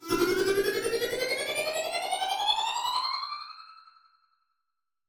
SouthSide Trap Transition (22).wav